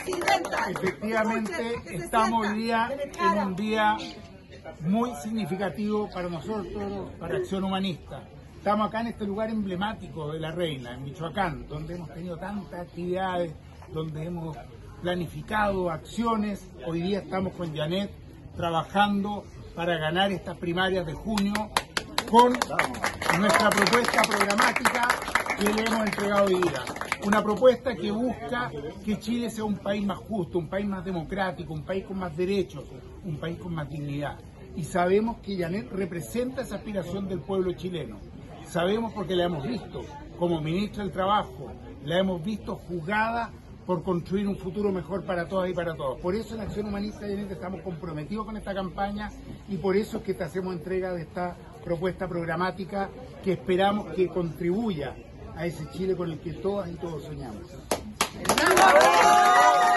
En la Casa Museo Michoacán de los Guindos, en la comuna de La Reina, la candidata presidencial Jeannette Jara, recibió de manos del Equipo de Coordinación Nacional del partido Acción Humanista su propuesta para nutrir el futuro programa de Gobierno, de cara a las primarias presidenciales de Unidad por Chile, el 29 de junio.
Para Tomás Hirsch, presidente de Acción Humanista, esta jornada marcó un hito político relevante: “Efectivamente, amigos, estamos en un día muy significativo para nosotros, para Acción Humanista.